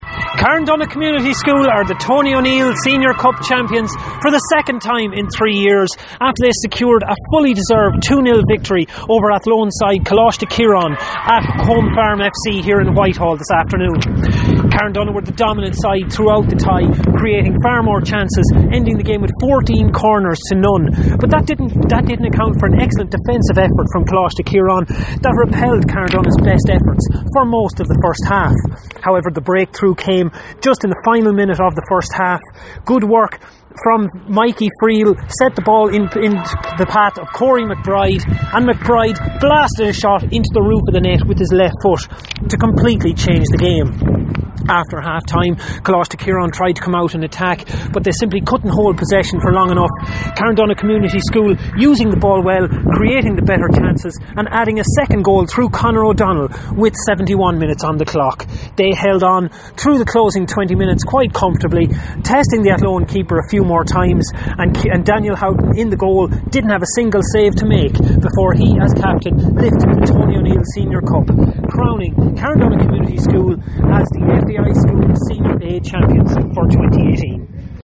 Carndonagh-report-for-Highland-Radio.mp3